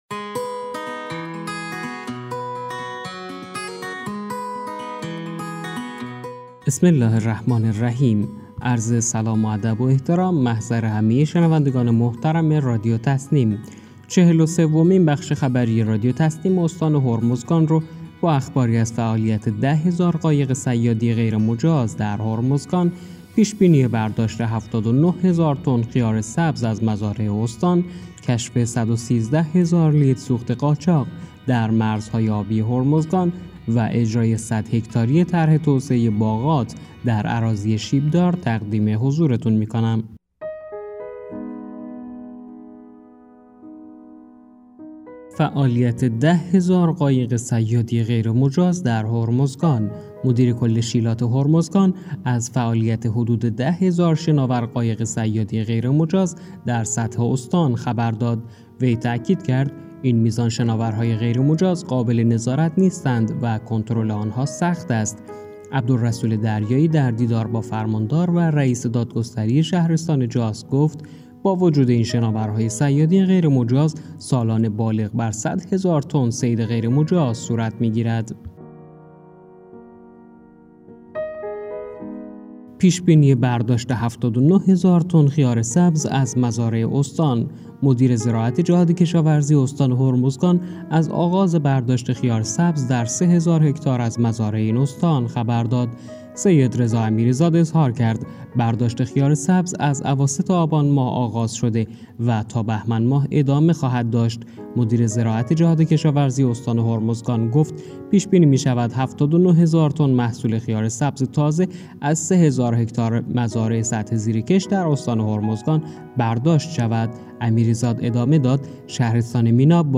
گروه استان‌ها- چهل و سومین بخش خبری رادیو تسنیم استان هرمزگان با بررسی مهم‌ترین اخبار این استان در 24 ساعت گذشته منتشر شد.